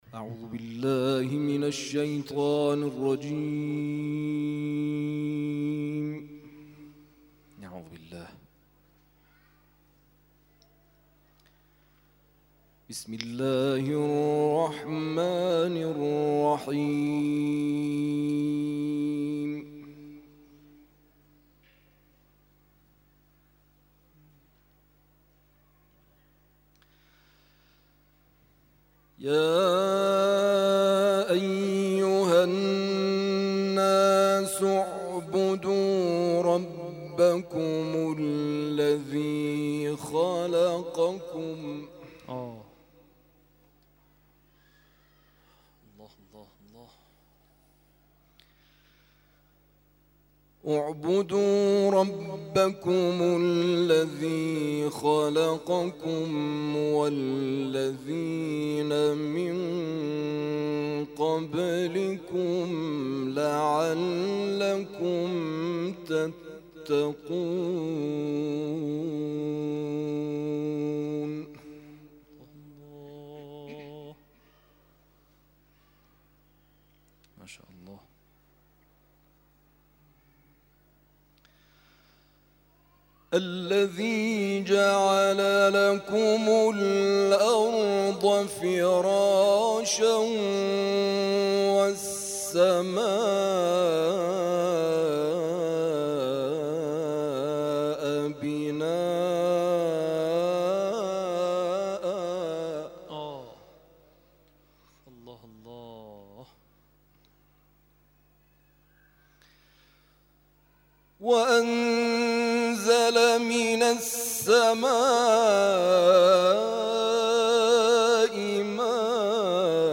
تلاوت
در محفل هفتگی آستان عبدالعظیم(ع)